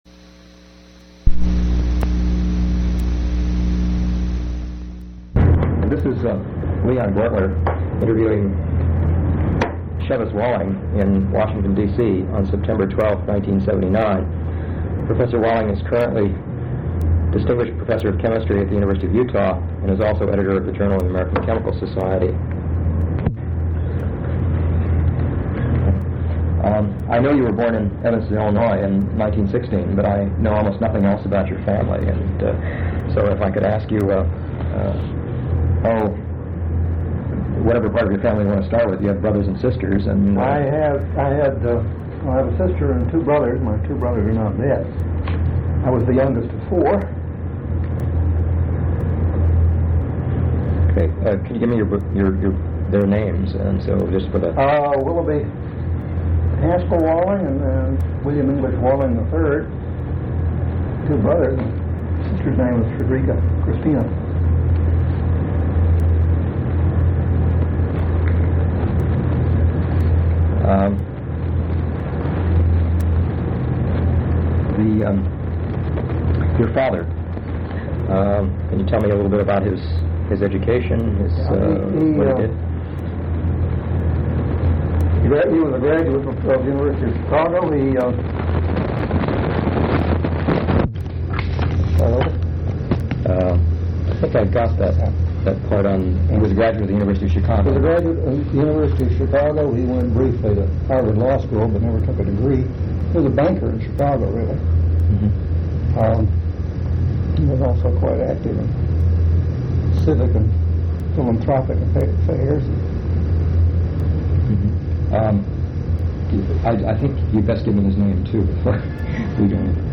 Oral histories
Mayflower Hotel (Washington, D.C.)